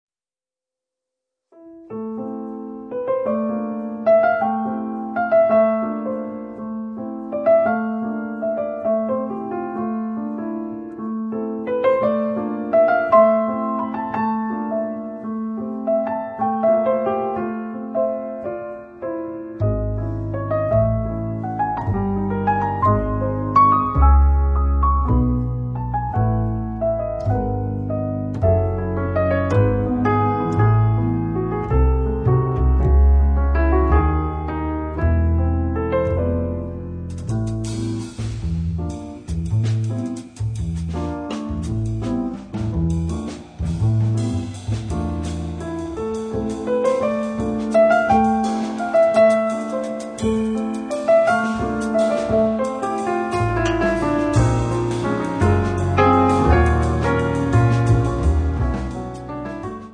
pianoforte, samplers, laptop, moog piano system
contrabbasso, basso elettrico
batteria